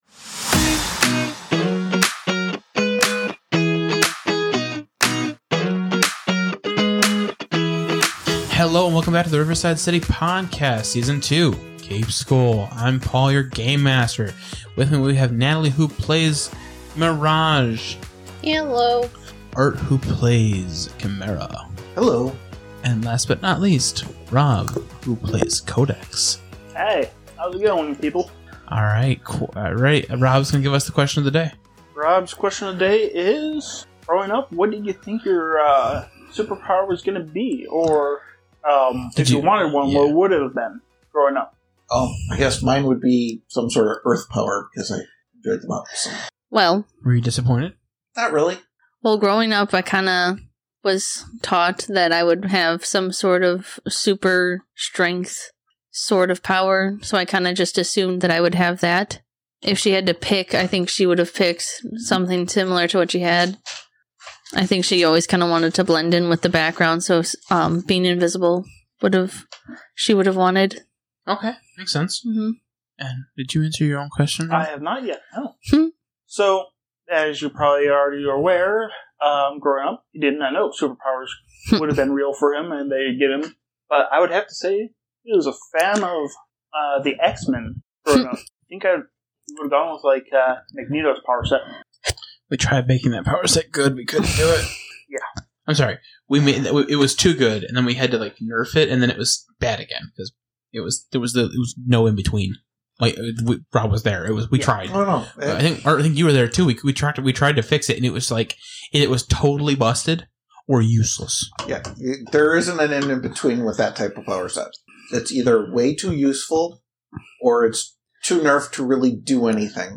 Actual Play
Audio Drama